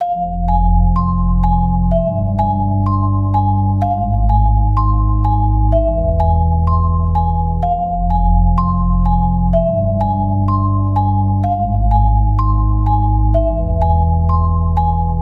Horror Musicbox_Main Bell.wav